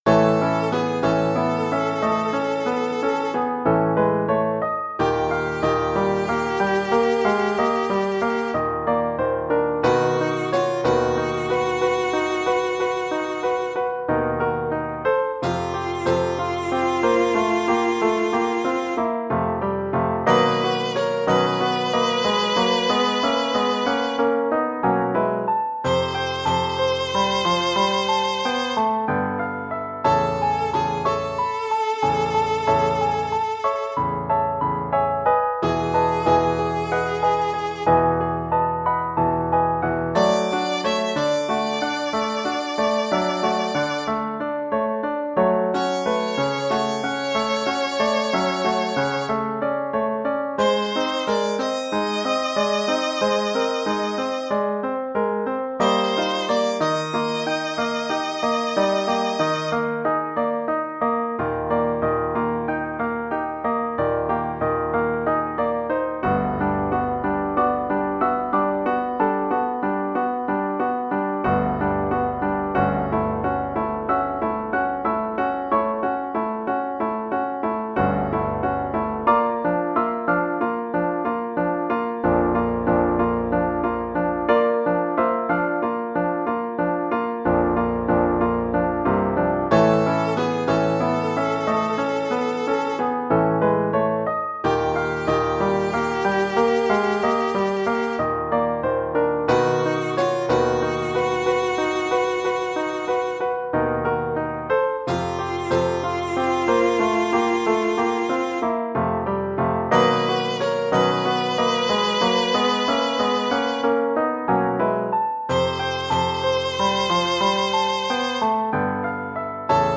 The MP3s below are exported from the MIDI.